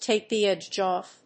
tàke the édge òff…